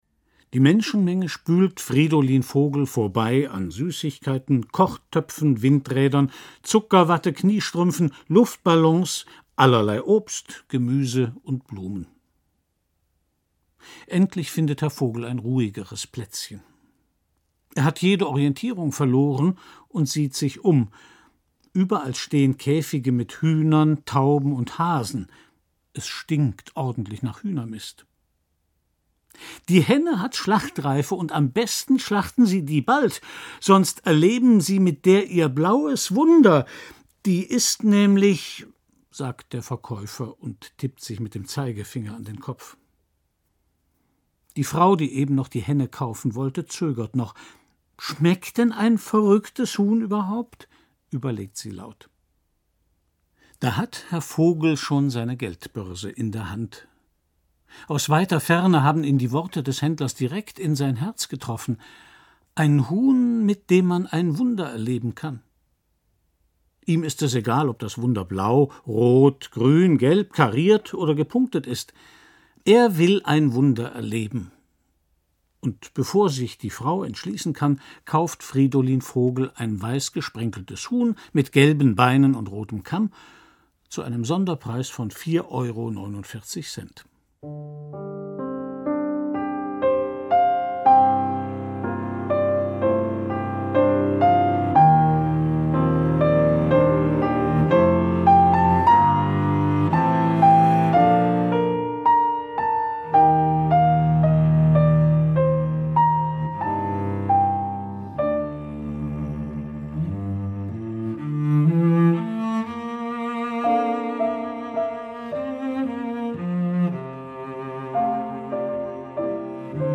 Klarinette
Violoncello
Klavier